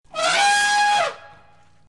Download Elephant sound effect for free.
Elephant